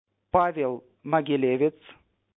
NOMBREPRONUNCIACIÓNHABLANTE NATIVOHISPANOHABLANTE
Pavel MOGILEVETSPáyel Maguilévets